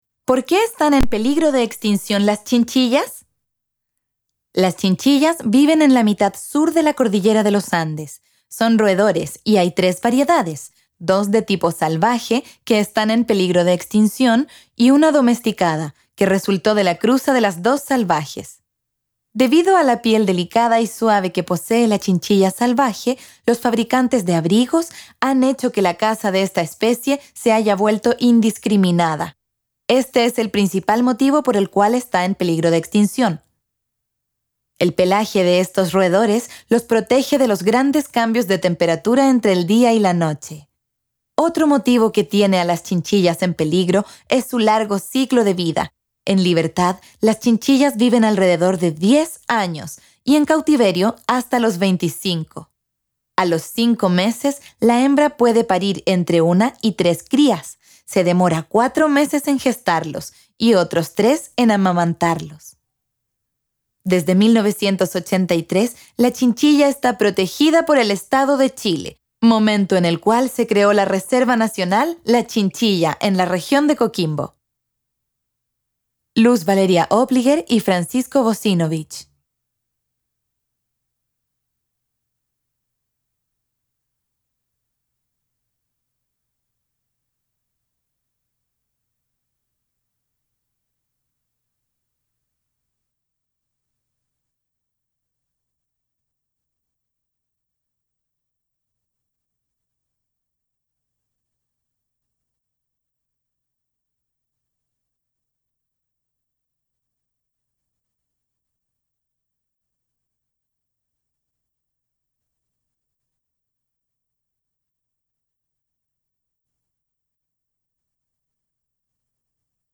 Videos y Audiocuentos